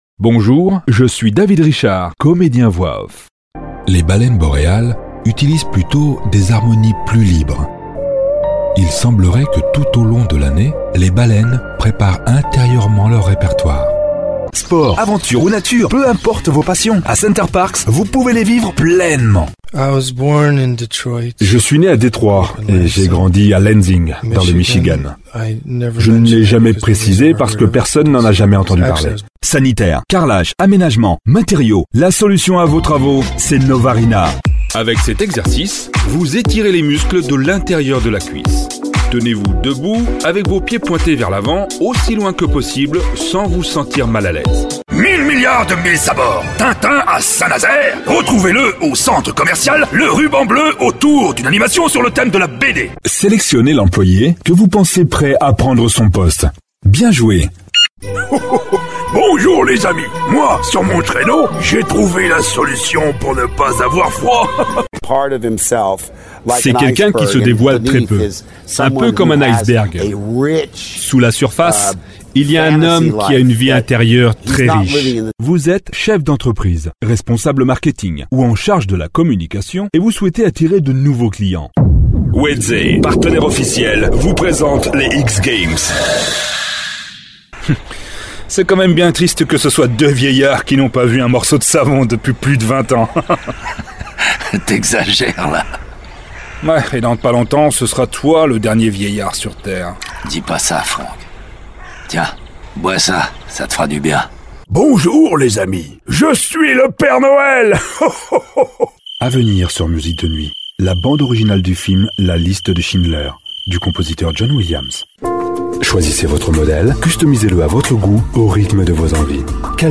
Profonde, Naturelle, Chaude, Douce, Commerciale, Polyvalente
Corporate